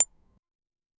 Soft, quick, and gentle click sound of metal cube tapping on wood deck. Pleasant, muffled, velvety tone. UI sound effect.
soft-quick-and-gentle-cli-xdx3auwm.wav